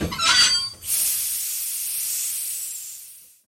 breaks_sound.ogg